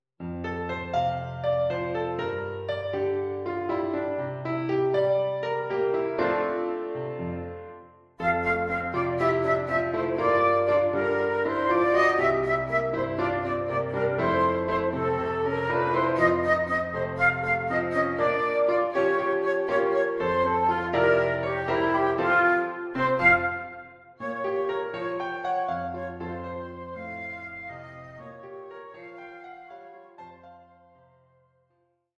Four Easy Pieces for Flute and Piano